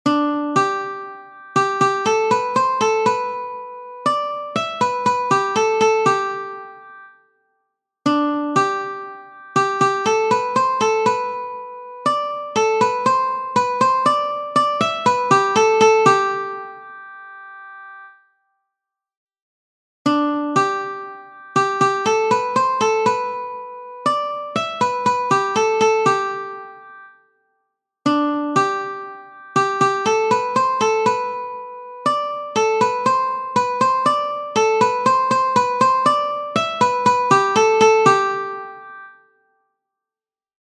Exercise 1: 4/4 time signature.